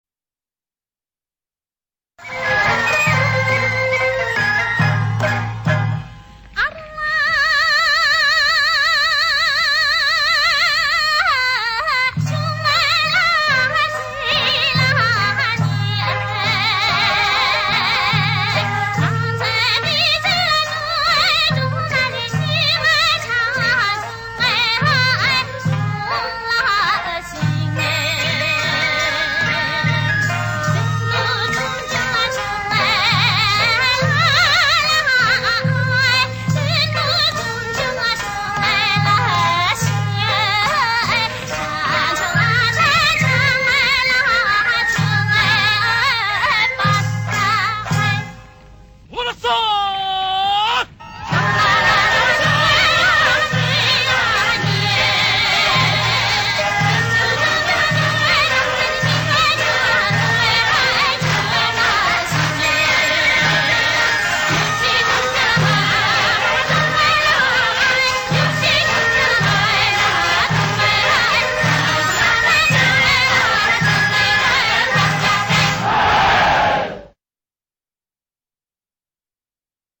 藏族民歌
（藏语演唱）